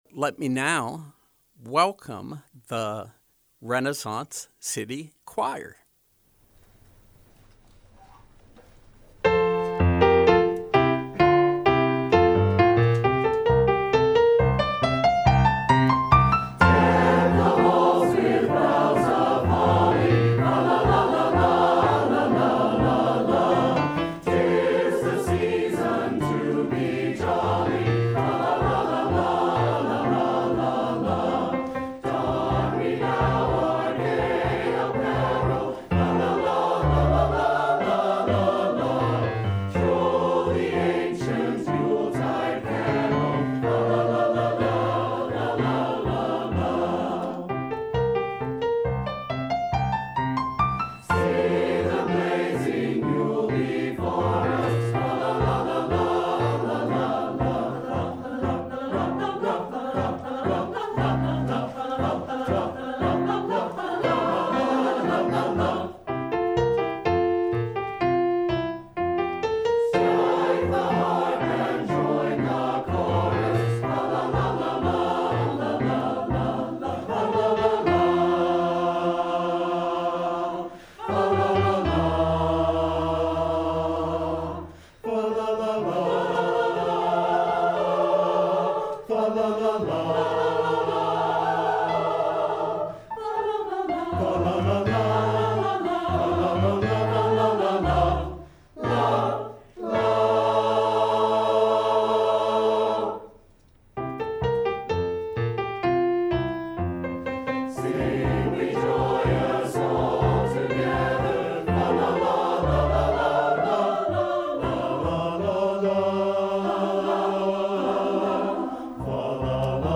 Live Music: Renaissance City Choir
From 11/19/2022: Live music and conversation with Renaissance City Choir.